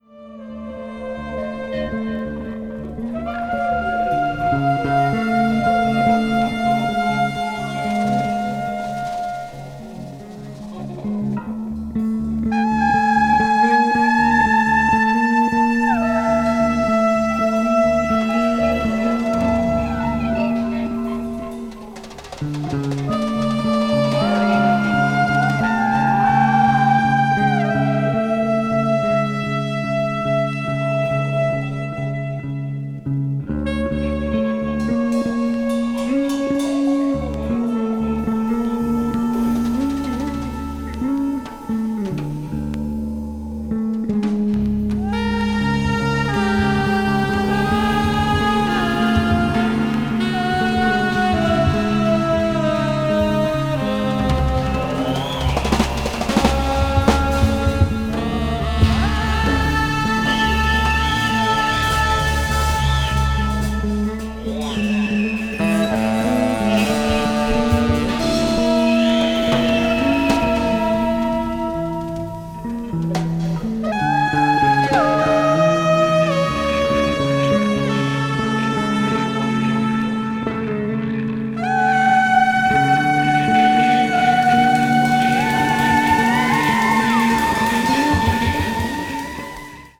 contemorary jazz   deep jazz   jazz rock